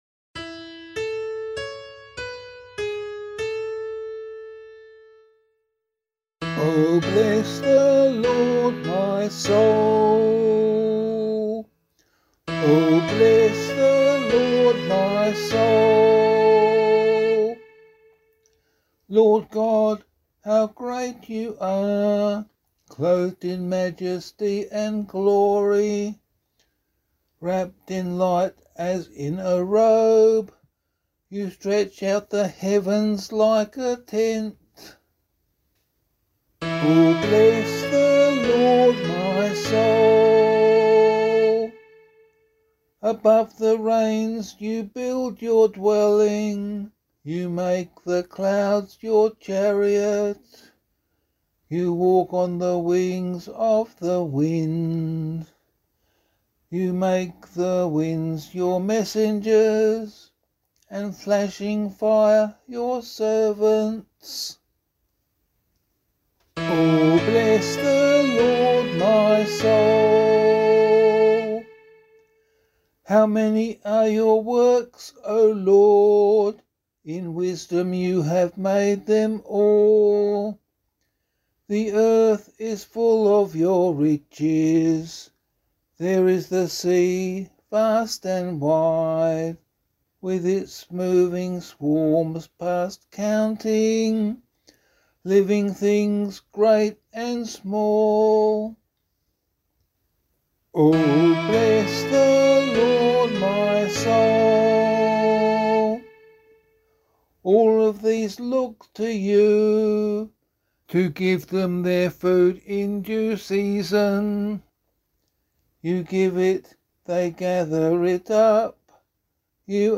The Holy Family of Jesus, Mary and Joseph: Responsorial Psalm, Year C option
011 Baptism of the Lord Psalm C [LiturgyShare 8 - Oz] - vocal.mp3